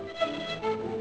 violin